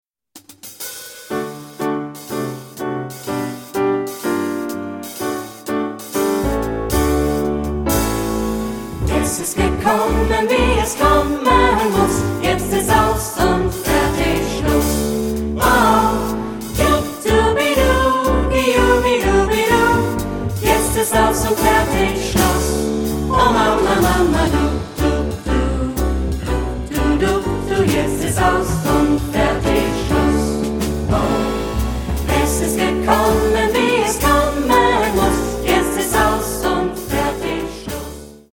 Ad libitum (3 voices Ad libitum).
Partsong. Canon. Choral jazz.
Mood of the piece: jazzy ; rhythmic ; light
Tonality: D major